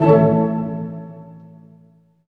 Index of /90_sSampleCDs/Roland LCDP08 Symphony Orchestra/HIT_Dynamic Orch/HIT_Tutti Hits